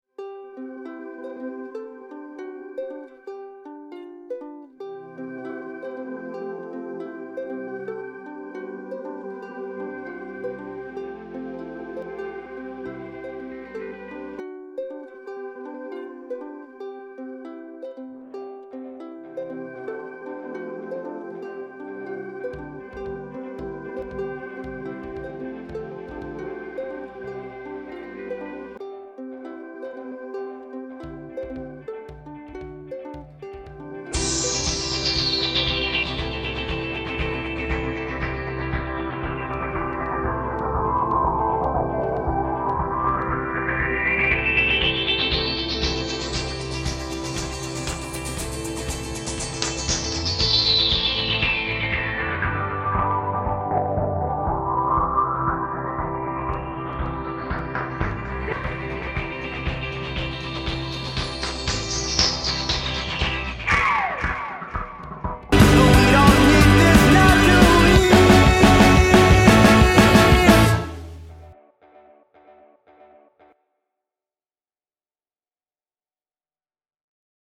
I don't know how to make audio that sounds like a human because I am not a genius so instead I tried to make a song that sounds like the inside of my head but my computer made scary noises and nothing really transitioned... so this is what the inside of my head would sound like if I got a lobotomy or something.